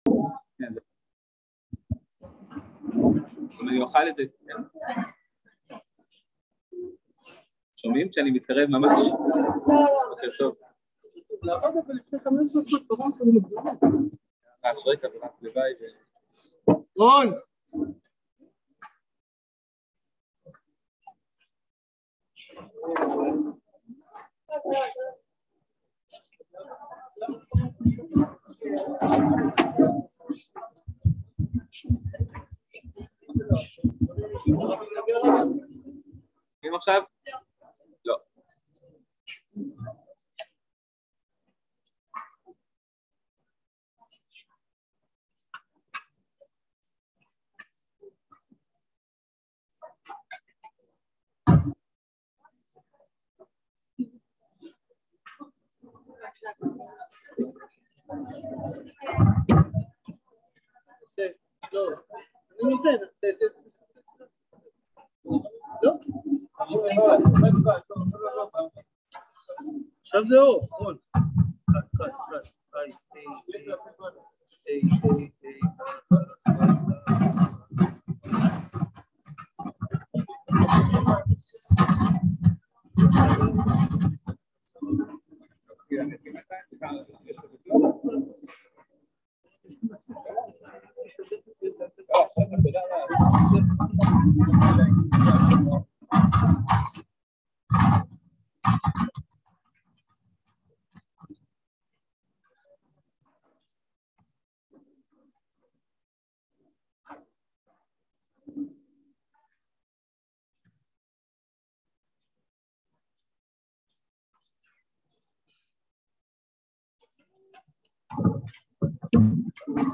הסיפור של אסתר המלכה | יום עיון תשפ"ב | מדרשת בינת